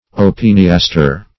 Search Result for " opiniaster" : The Collaborative International Dictionary of English v.0.48: Opiniaster \O`pin*ias"ter\, Opiniatre \O`pin*ia"tre\, a. [OF. opiniastre, F. opini[^a]tre.